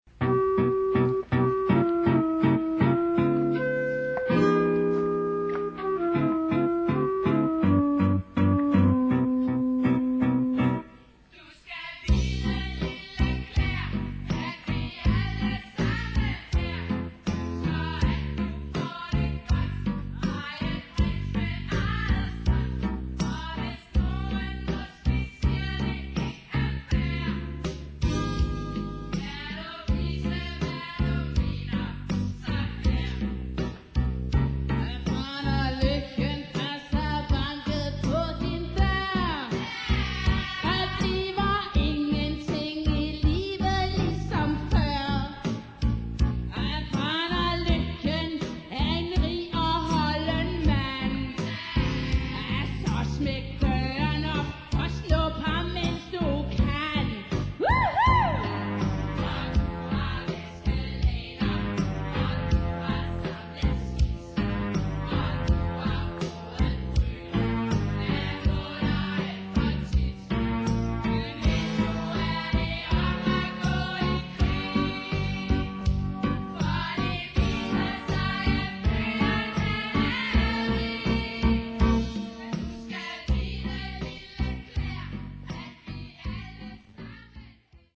"live on stage!"